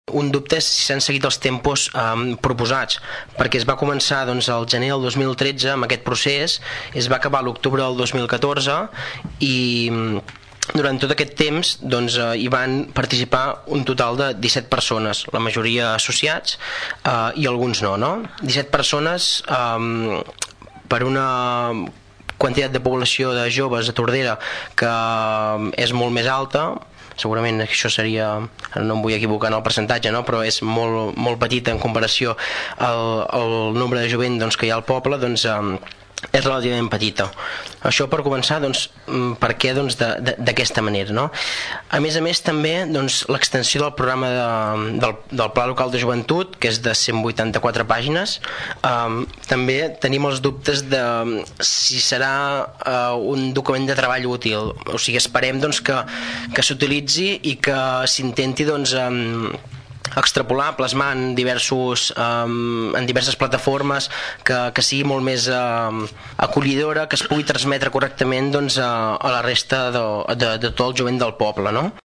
Des d’ERC, Jordi Romaguera va remarcar positivament algunes de les idees del pla, però va posar en dubte que es tracti d’un projecte de futur, perquè més de la meitat de les propostes ja s’han executat i només queden dos anys per endavant. En aquest sentit, es qüestionen els terminis i també si el nombre de joves que hi ha participat és prou representatiu.